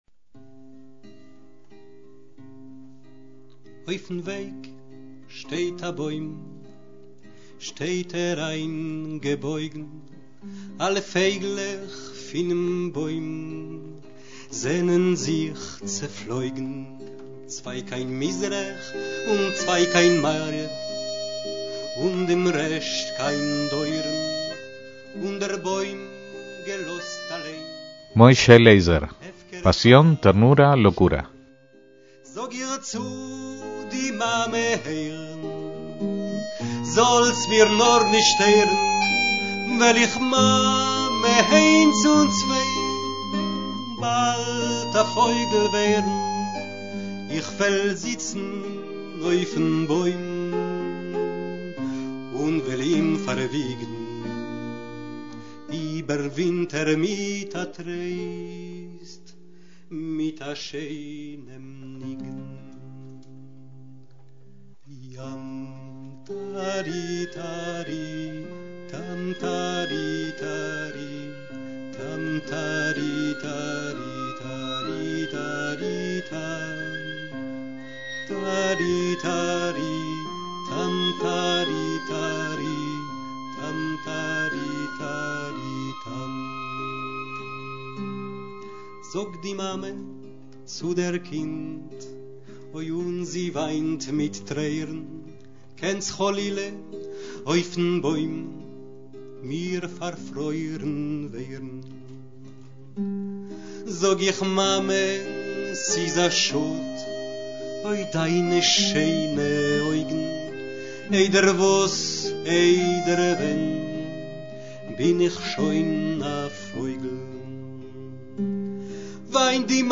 MÚSICA ÍDISH
cantando y tocando la guitarra